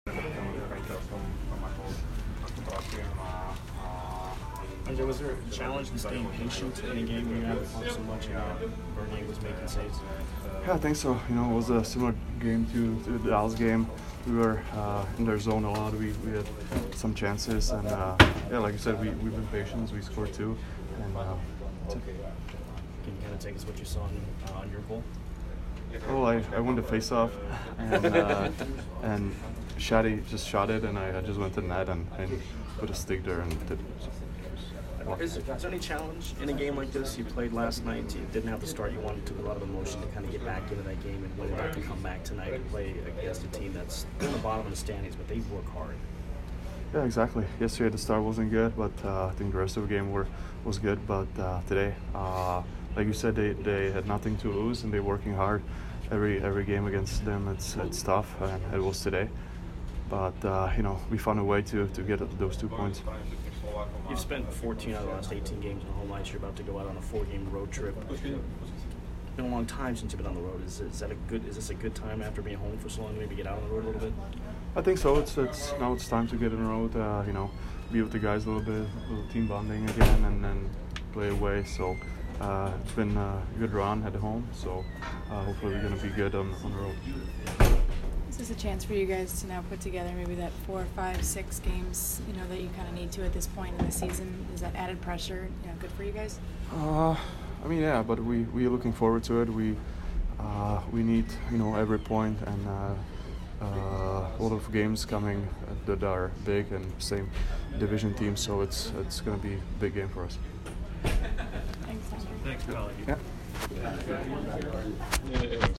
Palat post-game 12/29